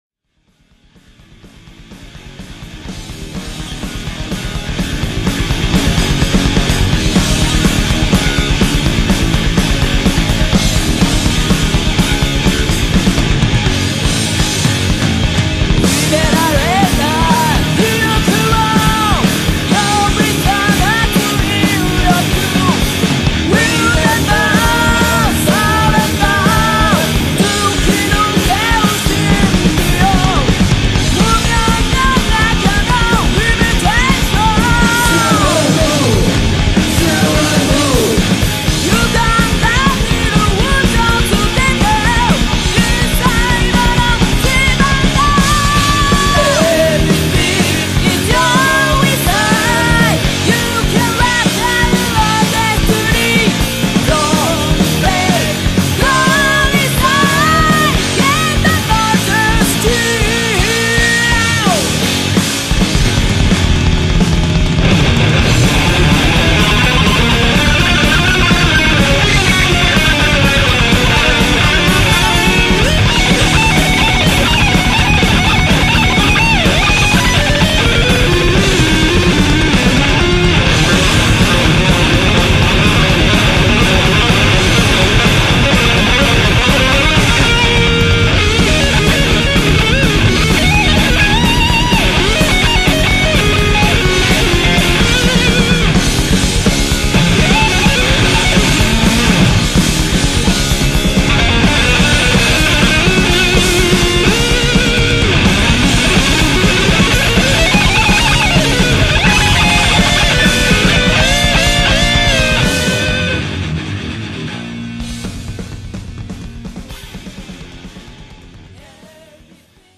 女性ヴォーカルをフィーチャーしたハード・ロック・バンドで
古き良き時代のハード・ロック・サウンド。